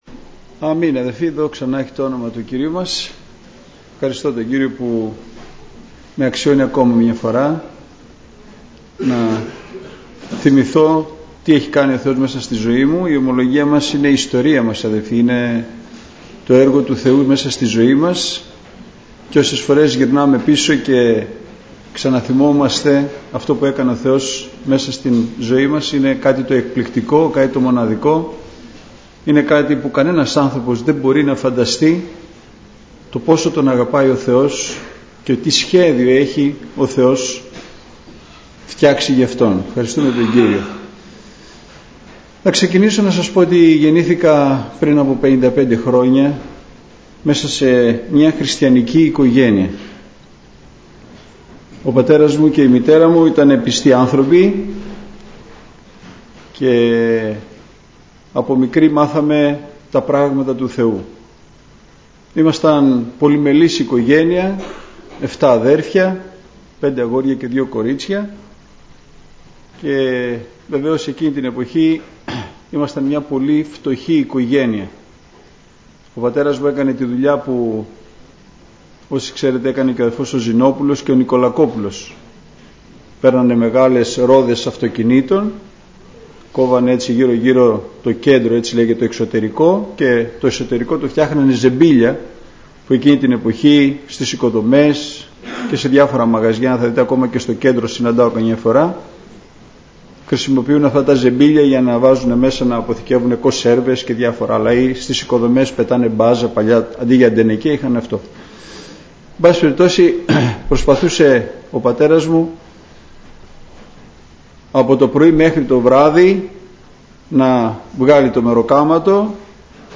Ομολογίες